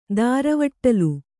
♪ dāravaṭṭalu